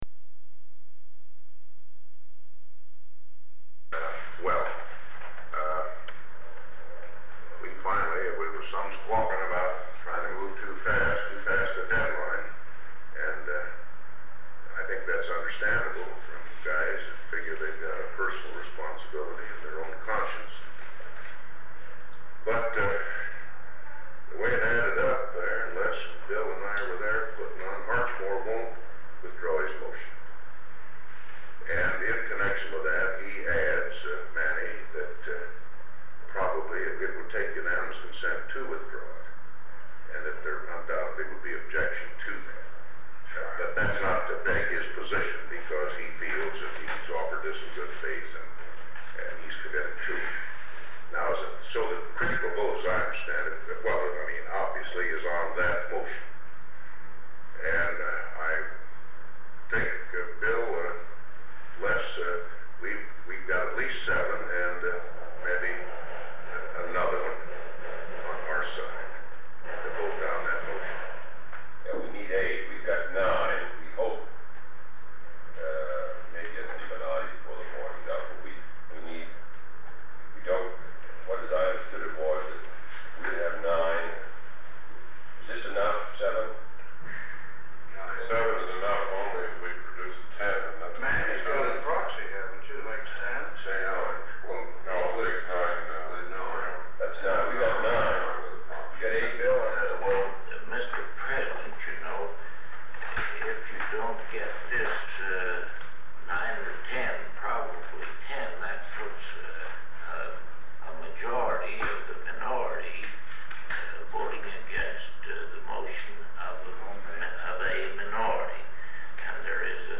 Sound recording of a meeting on civil rights legislation held on October 29, 1963, between President John F. Kennedy; Vice President Lyndon B. Johnson; Deputy Attorney General Nicholas deB. Katzenbach; Representative Emanuel Celler (New York); Minority Leader, Representative Charles Halleck (Indiana); Representative William McCulloch (Ohio); Speaker of the United States House of Representatives John McCormack (Massachusetts); Representative Leslie Arends (Illinois); Assistant Attorney General Bu
Secret White House Tapes | John F. Kennedy Presidency Meetings: Tape 118/A54.